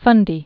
(fŭndē), Bay of